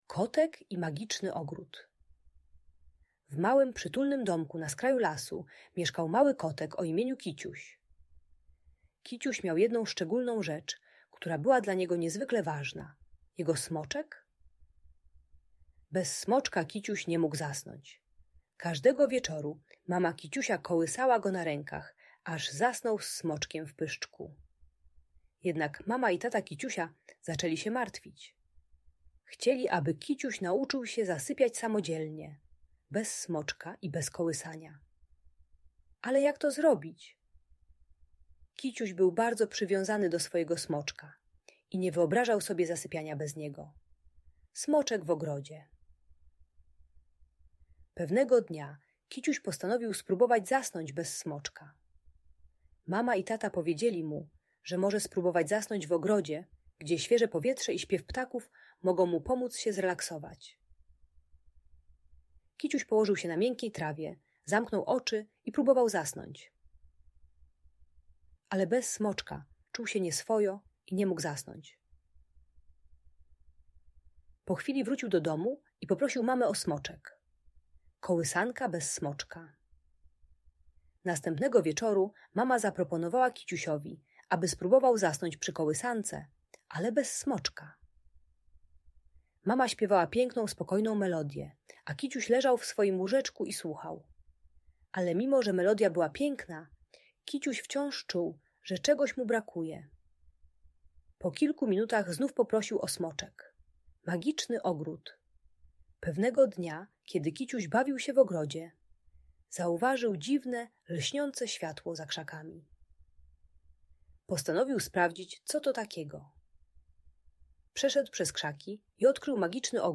Kotek i Magiczny Ogród - Urocza Historia na Dobranoc - Audiobajka